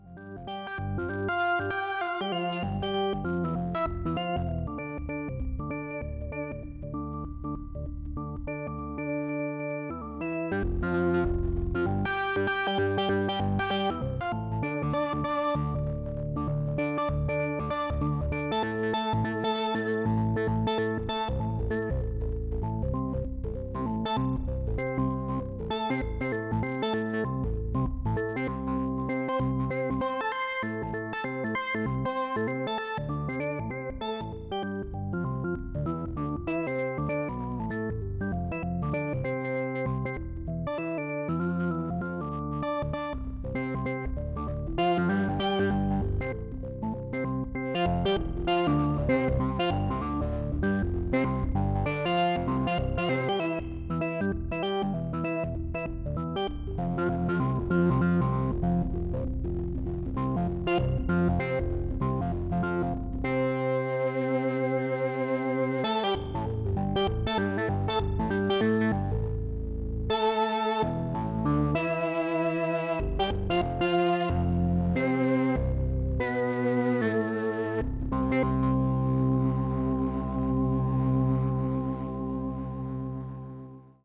Sound quality is excellent using a 12-bit DAC, 32kHz sample rate and 32-bit precision DSP computations.